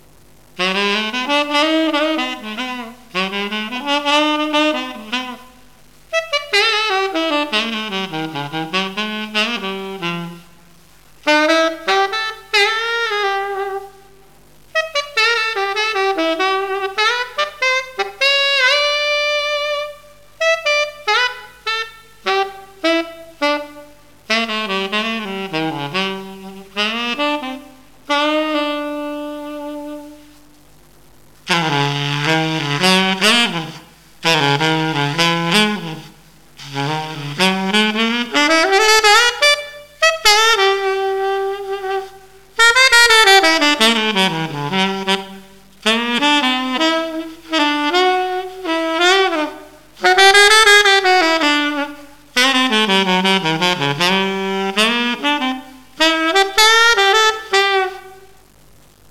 Martin C-Melody sax, very playable, rare 1920's instrument
Marked 'Low Pitch', which signifies standard, modern, A=440 pitch, so it plays in tune with modern instruments.
To hear a very basic (direct into the PC microphone) sound sample produced on the sax, please